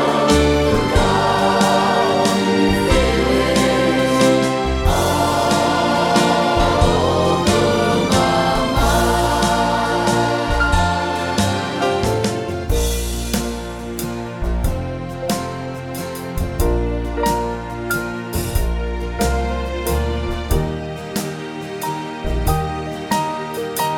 One Semitone Down Pop (1960s) 2:47 Buy £1.50